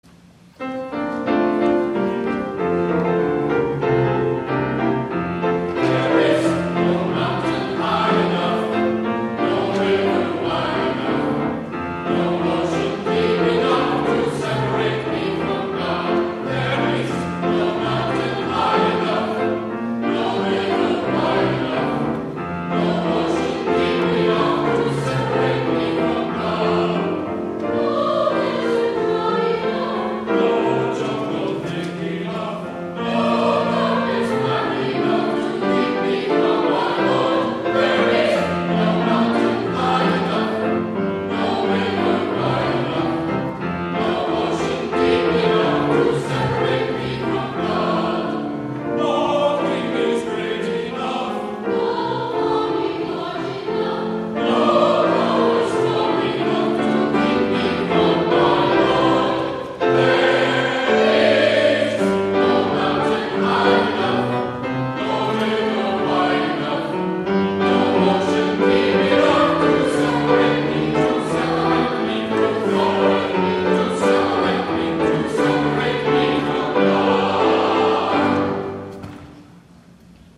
11 A.M. WORSHIP
THE ANTHEM